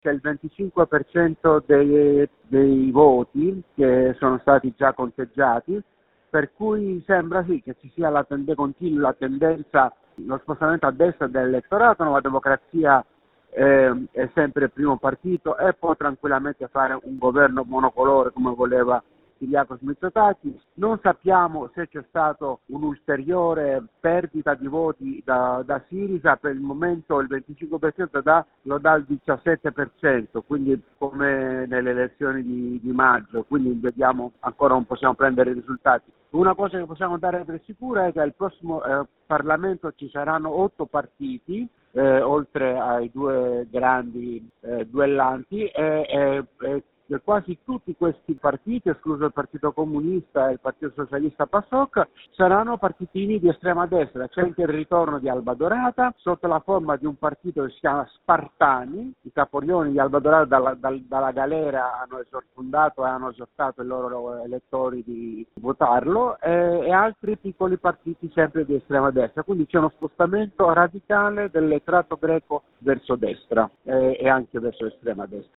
giornalista greco